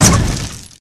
brute_impact_med1.wav